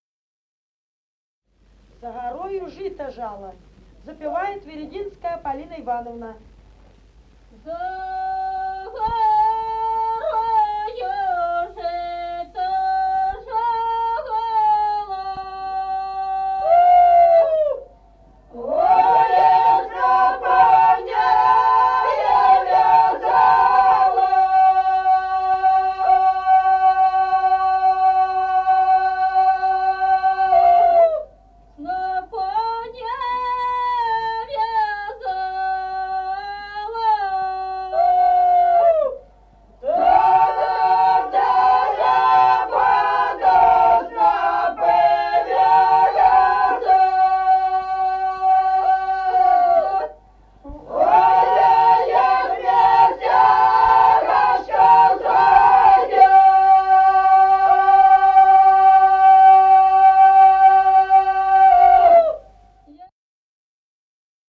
Народные песни Стародубского района «За горою жито жала», жнивная.
1953 г., с. Остроглядово.